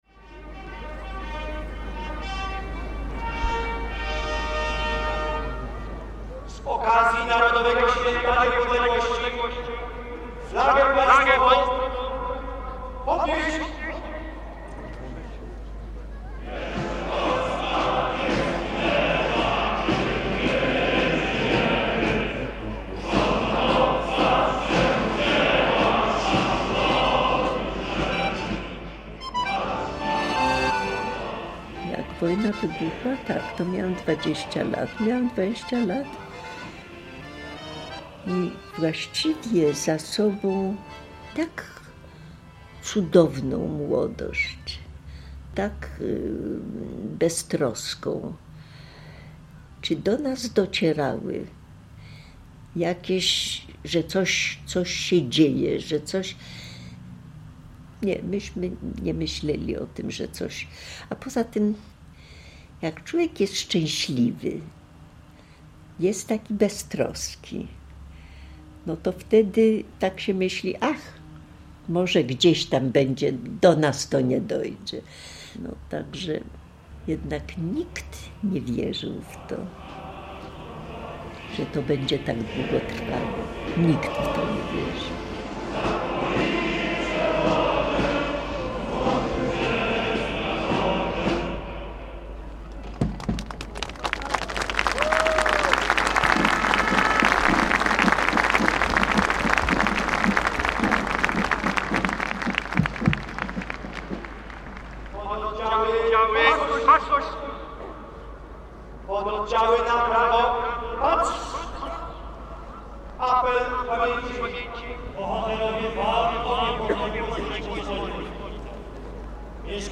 Fundacja Głos Ewangelii jest chrześcijańską organizacją i producentem audycji radiowych, z których najbardziej znanym formatem jest dokument radiowy – reportaż.